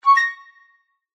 Flute.mp3